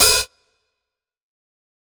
005_Lo-Fi Quick Open Hat.wav